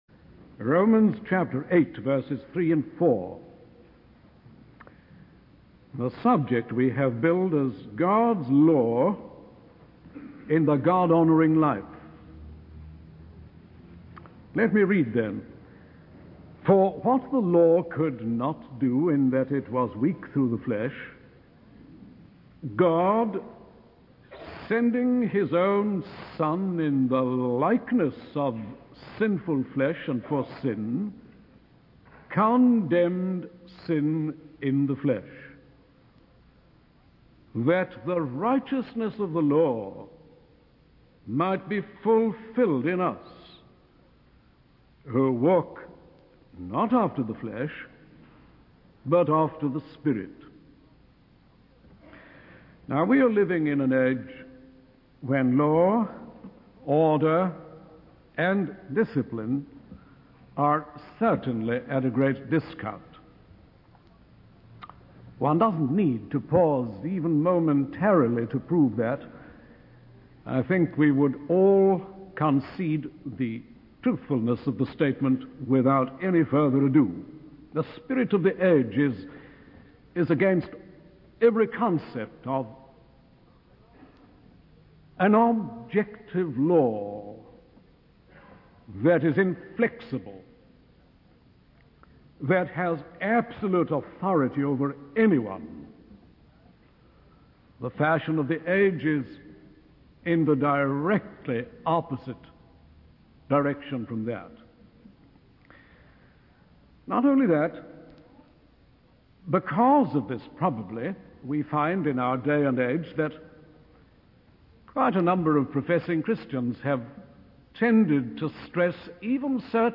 In this sermon on Romans chapter 8 verses 3 and 4, the preacher discusses the role of God's law in the God-honoring life. He emphasizes that the law of God is objective and has absolute authority, but in the flesh, humans are unable to fulfill its demands.